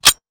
weapon_foley_pickup_16.wav